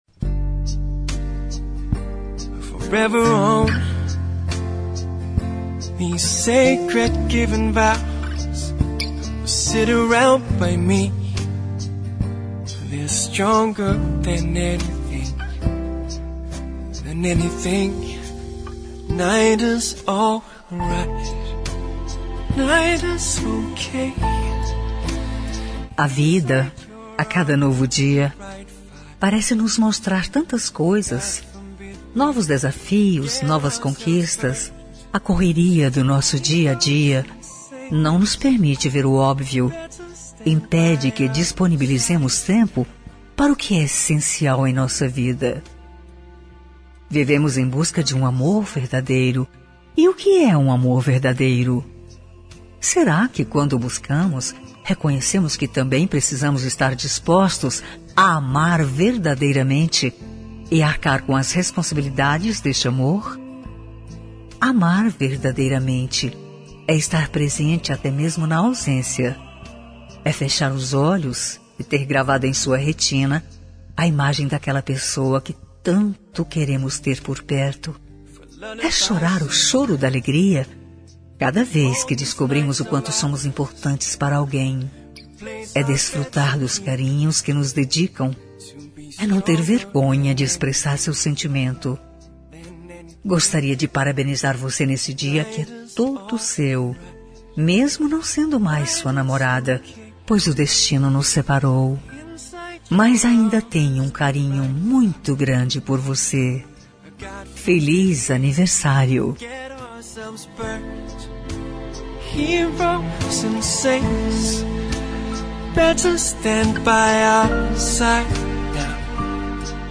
Telemensagem de Aniversário de Ex. – Voz Feminina – Cód: 1357